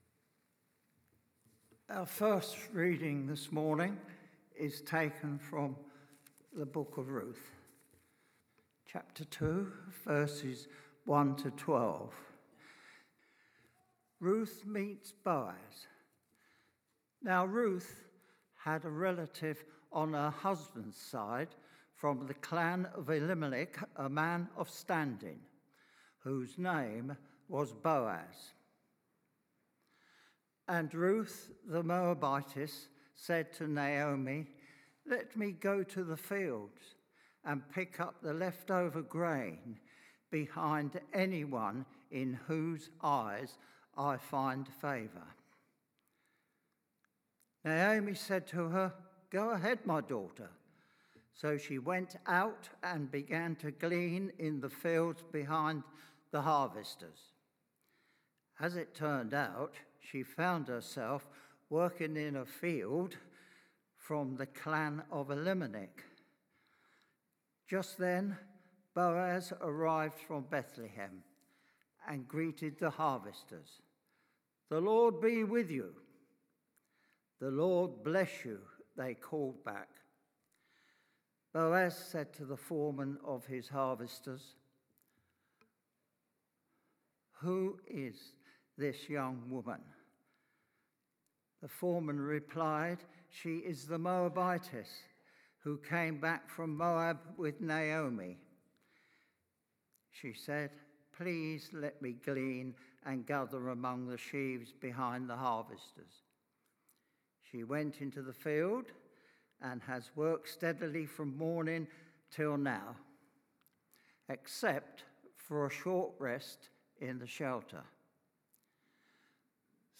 Emmanuel Church Sermons